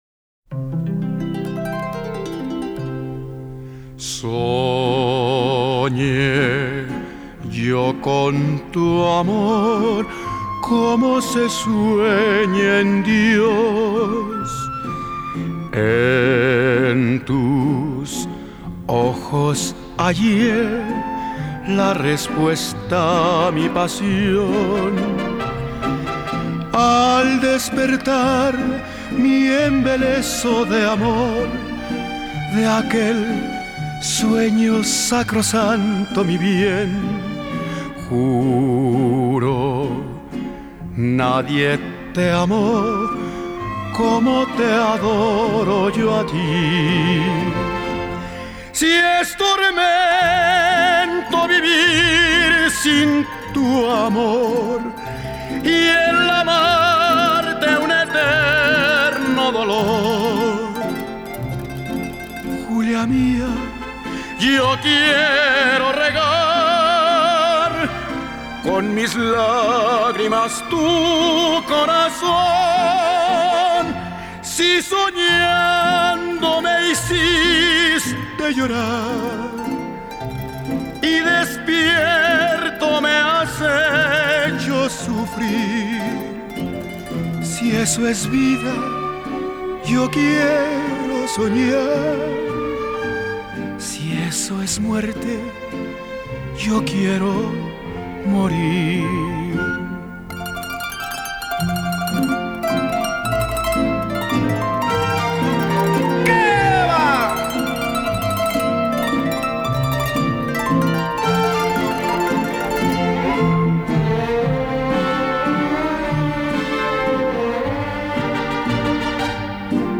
con mariachi